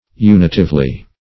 unitively - definition of unitively - synonyms, pronunciation, spelling from Free Dictionary Search Result for " unitively" : The Collaborative International Dictionary of English v.0.48: Unitively \U"ni*tive*ly\, adv.